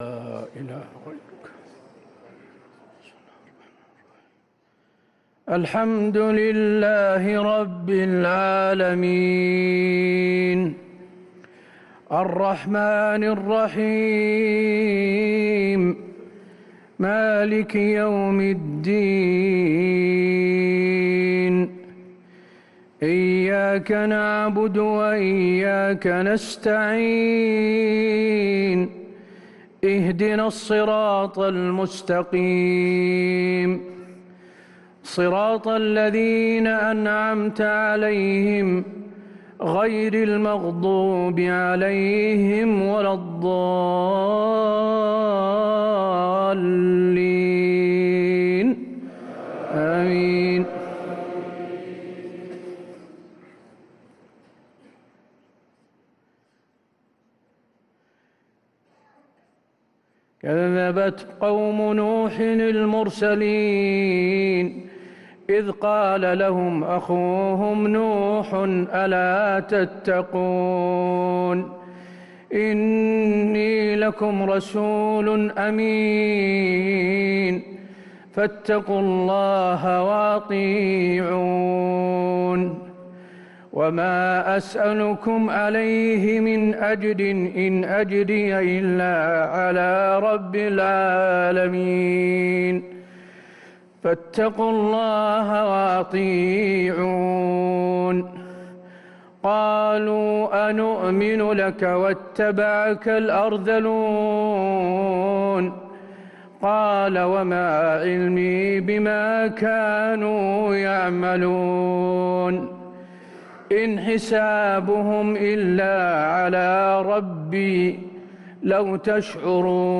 صلاة العشاء للقارئ حسين آل الشيخ 3 ربيع الأول 1445 هـ
تِلَاوَات الْحَرَمَيْن .